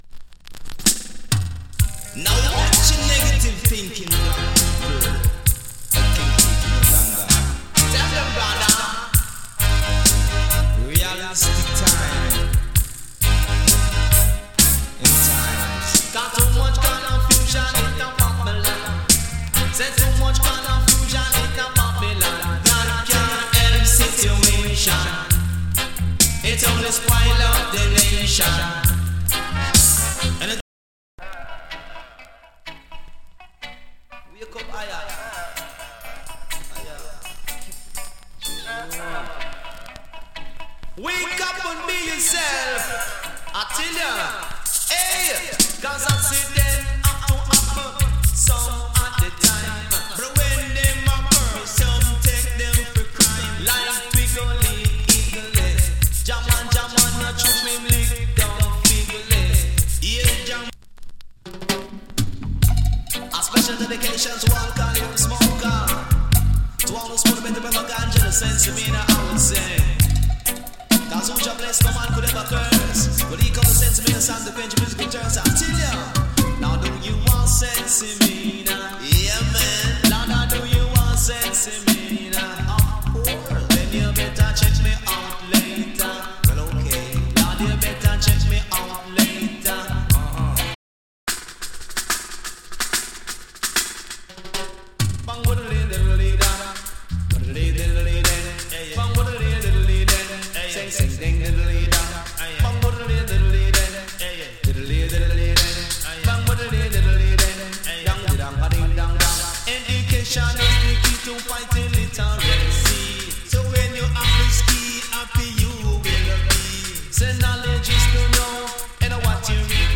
EARLY DANCE HALL DEE-JAY ALBUM